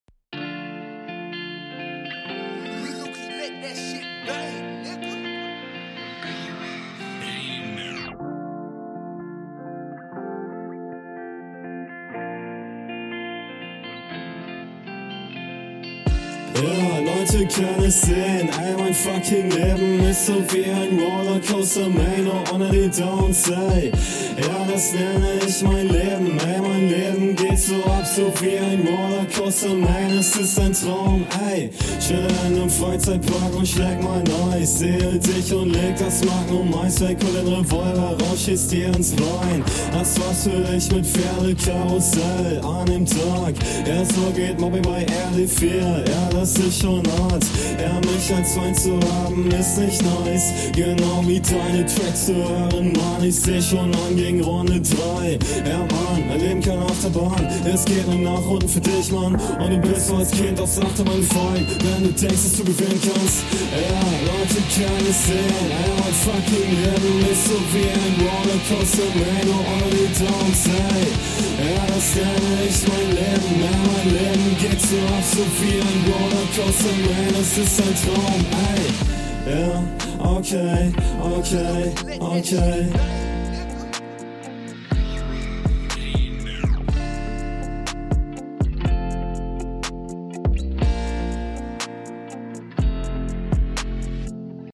Flowlich ist das wieder okay, teilweise kleine Variationen drin, Stimmeinsatz etwas zu drucklos, überwiegend im …
:D Vibet super! halt wieder …